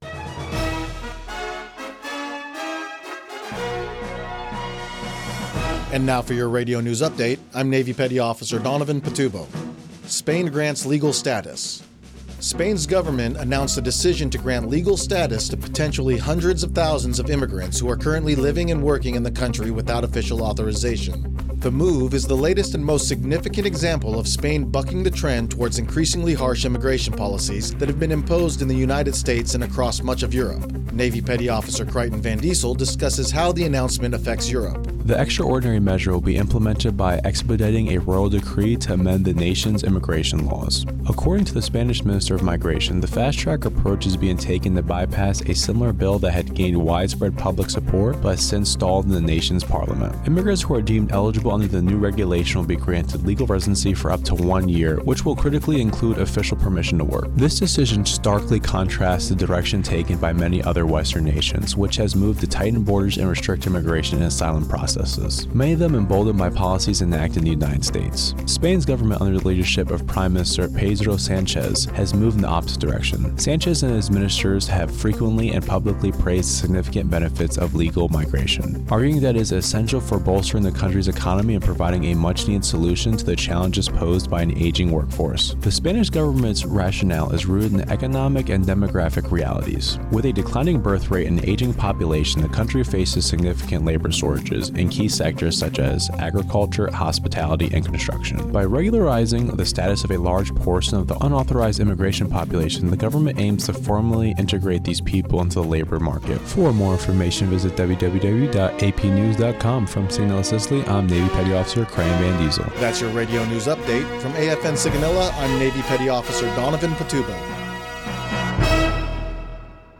Radio News 260129